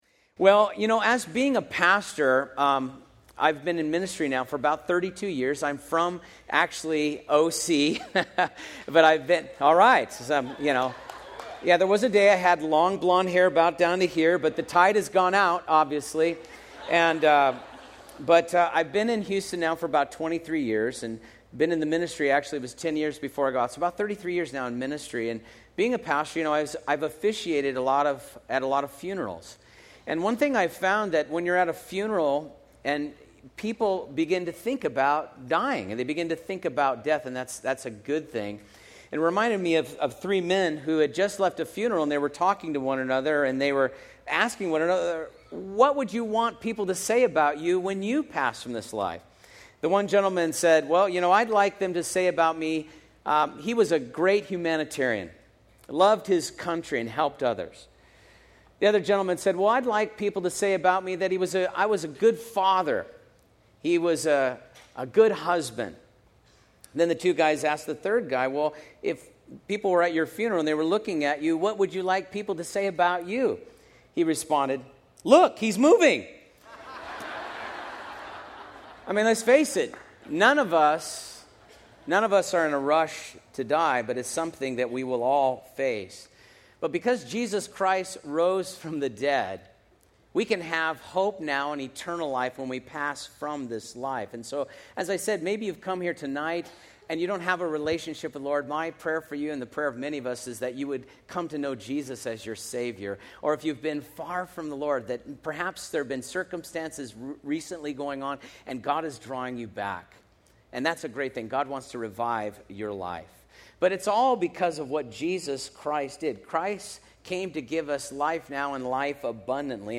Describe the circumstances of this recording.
Sermons Conferences 5 Nights Of Revival (2014) One One July 9